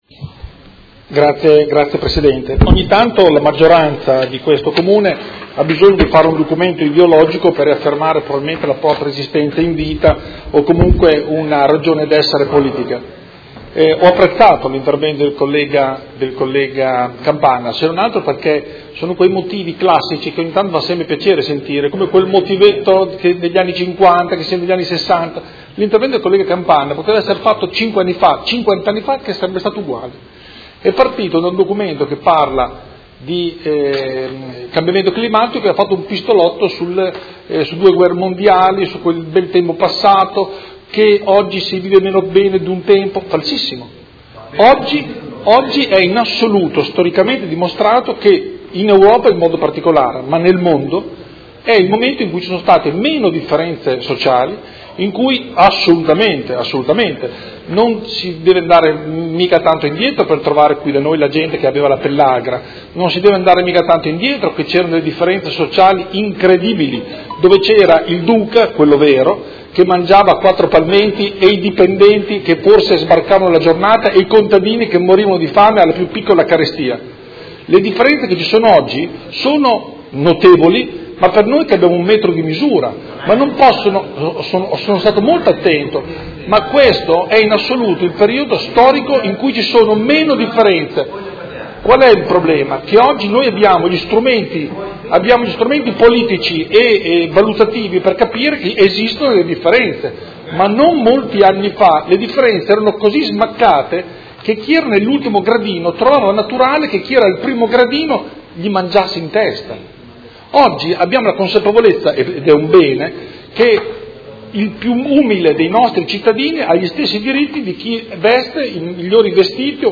Seduta del 14/03/2019. Dibattito su Mozione Prot. Gen. n. 72572